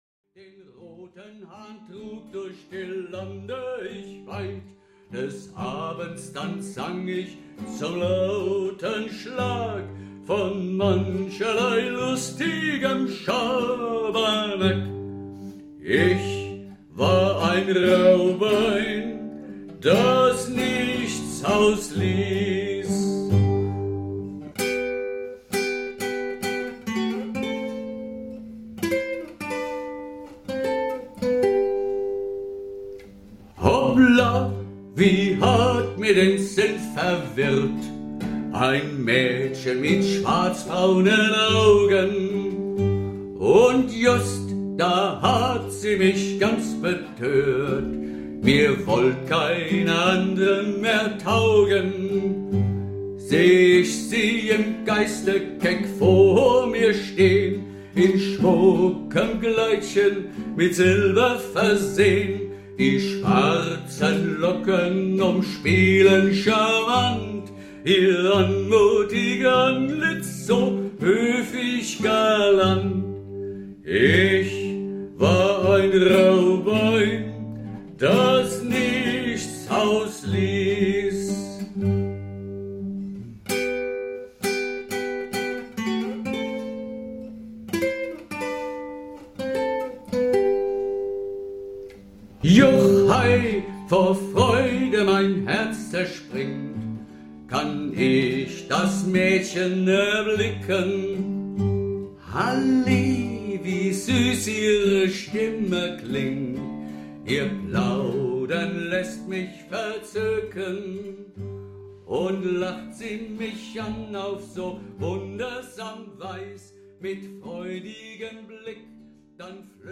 Werkstatt - Aufnahmen 21